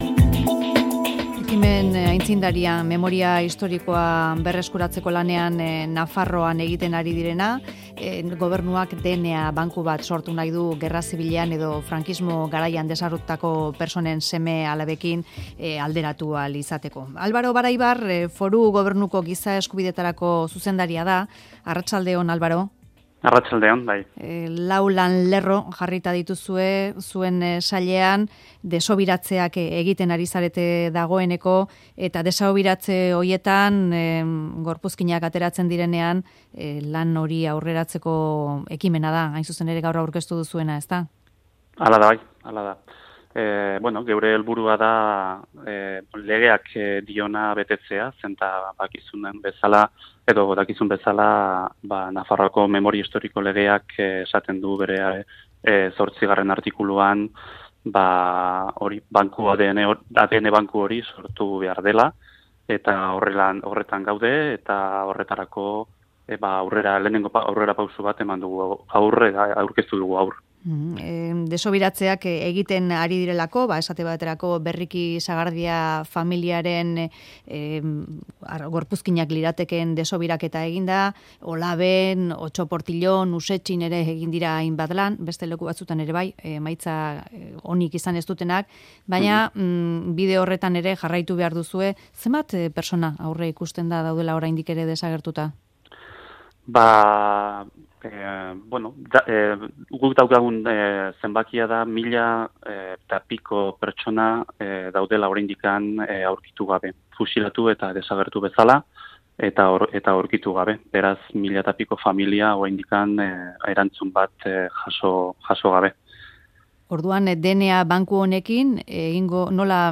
Alvaro Baraibar, Nafarroako Bake eta Giza eskubideetarako zuzendaria izan da Mezularian.